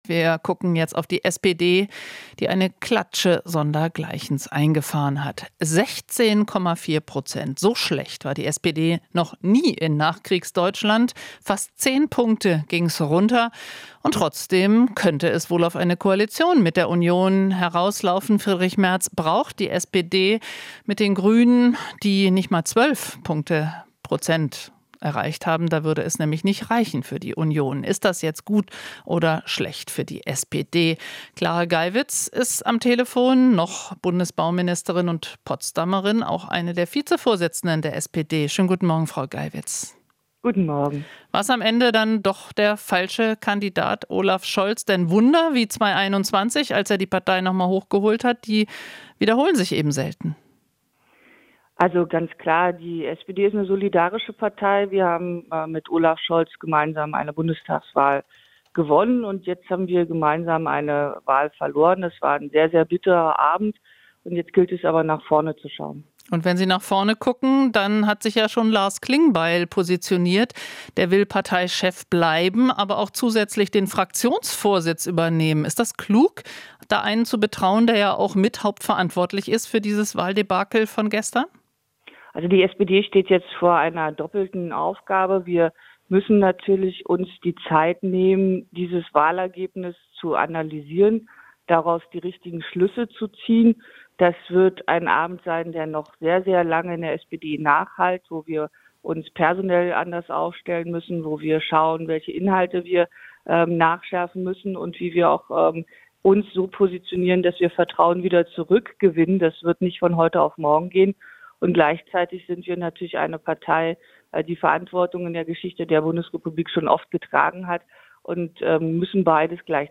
Interview - Geywitz (SPD) erwartet schwierige Koalitionsverhandlungen